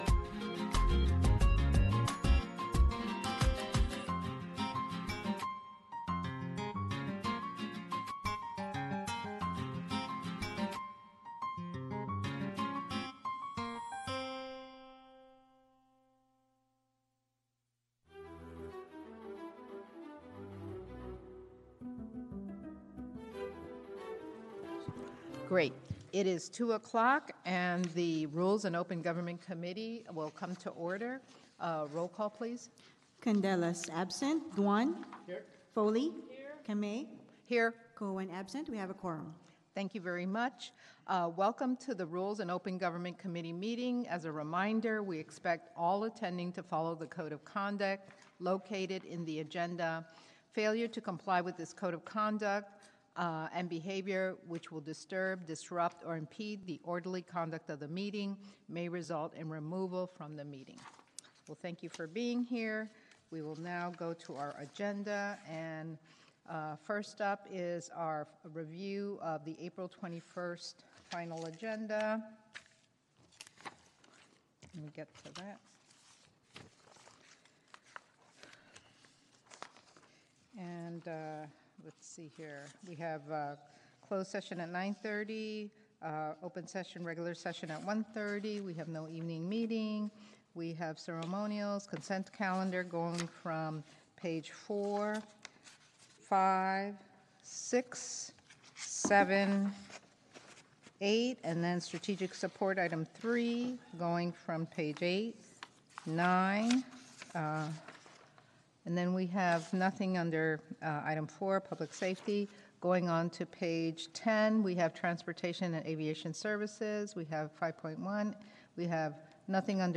You'll hear authentic audio capturing the voices of city officials, community leaders, and residents as they grapple with the local issues of the day.
Tuning in, you become a fly on the wall in council chambers and civic spaces, gaining insight into the complexities of urban management and community decision-making.